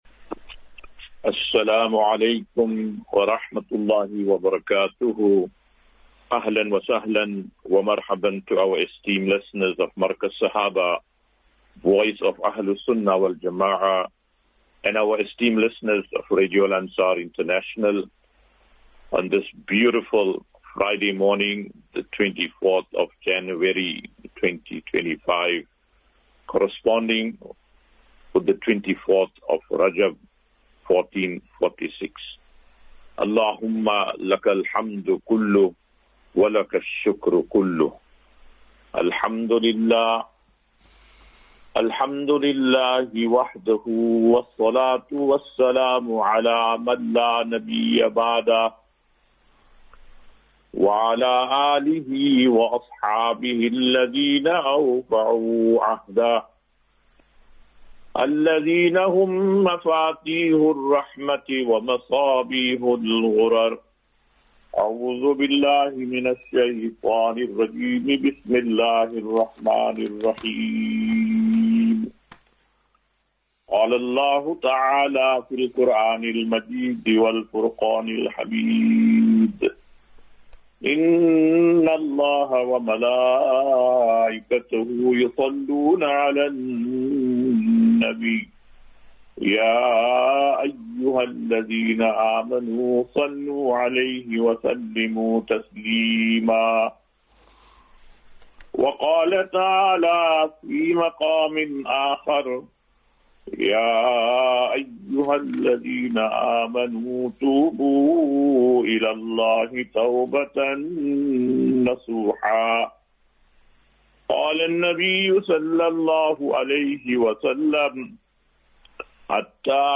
Assafinatu - Illal - Jannah. QnA.